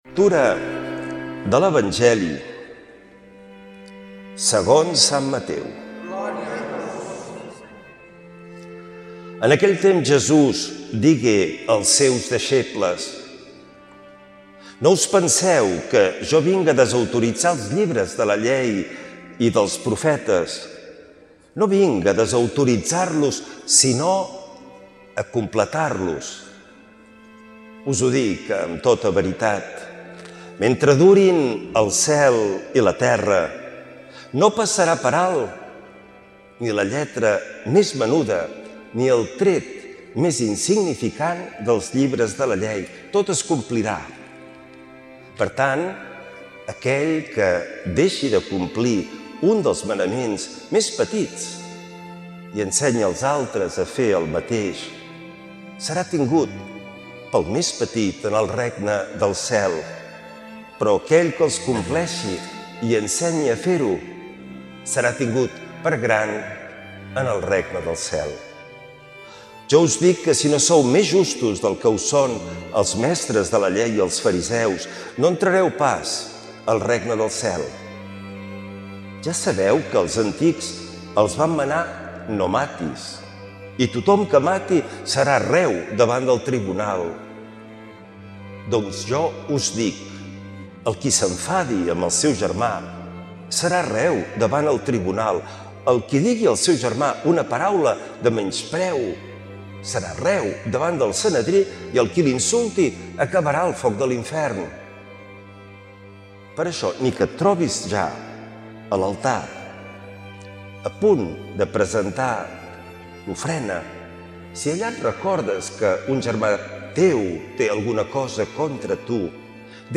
L’Evangeli i el comentari de diumenge 15 de febrer del 2026.